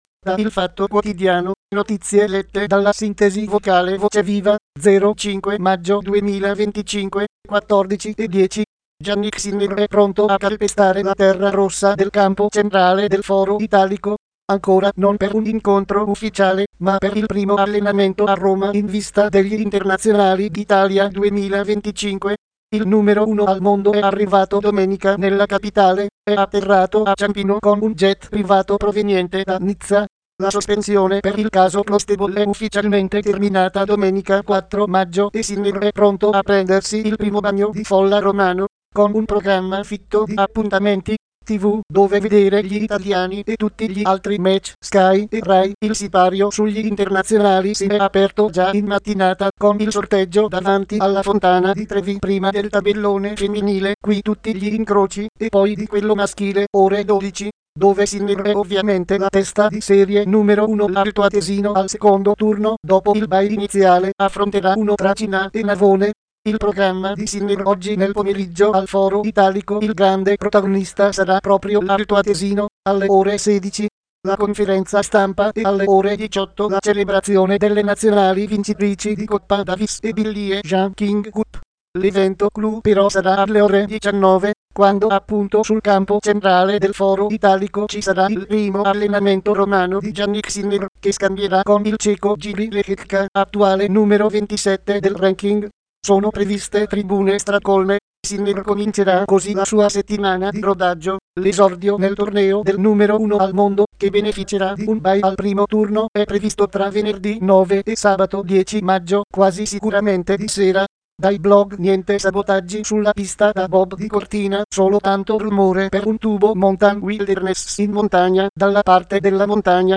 Nuova generazione di sintesi vocale
Software di sintesi vocale TTS - Text To Speech